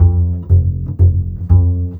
Rock-Pop 11 Bass 09.wav